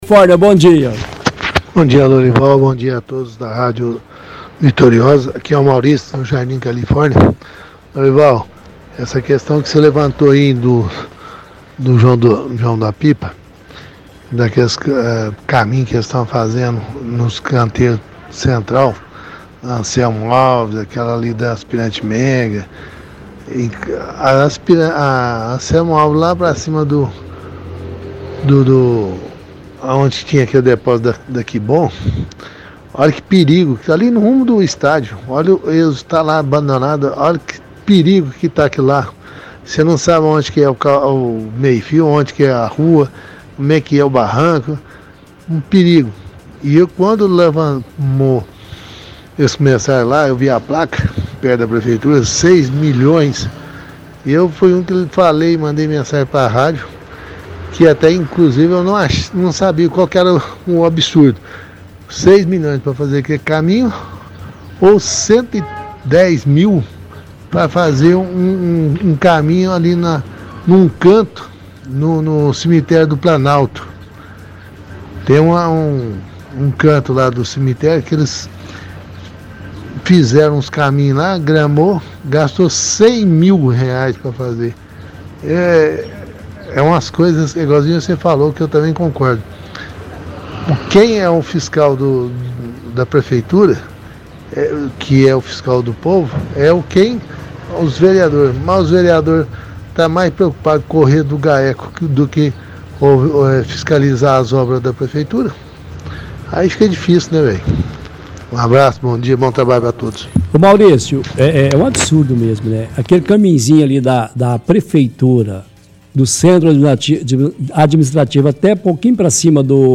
– Ouvinte questiona obra sendo realizada na AV. Anselmo Alves dos santos, cita que é um perigo pois não tem meio fio. Disse que a placa informa que os valores da obra e mais 6 milhões.